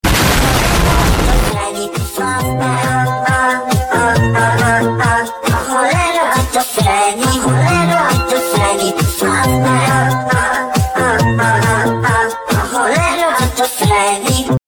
cow-faz-bear-made-with-Voicemod.mp3